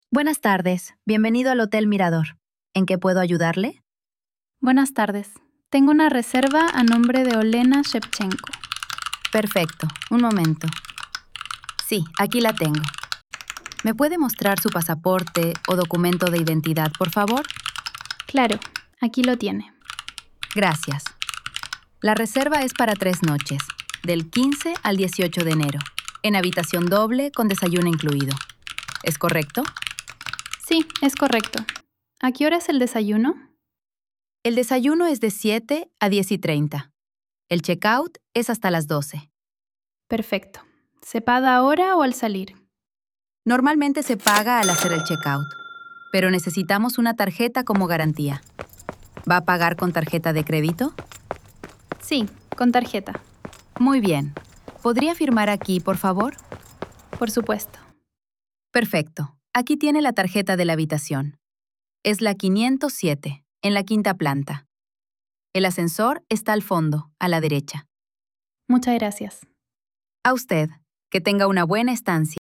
Diálogo · En recepción 00:00